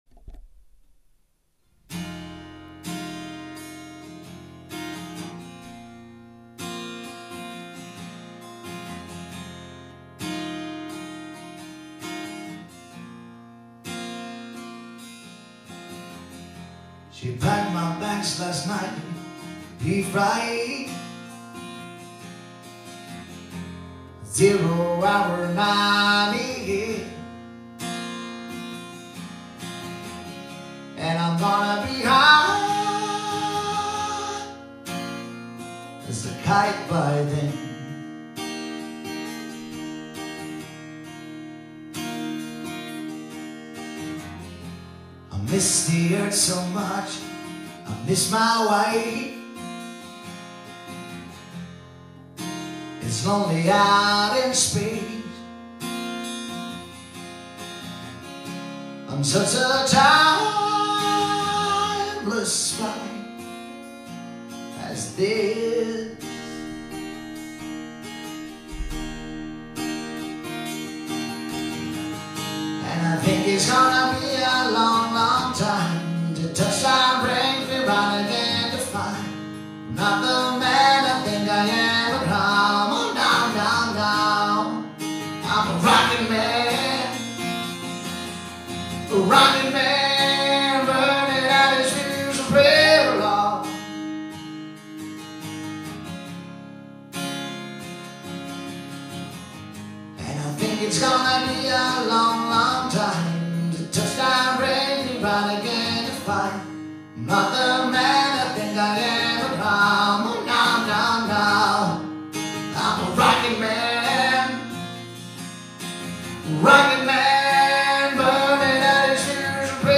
• Solomusiker